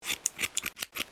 haircut2.wav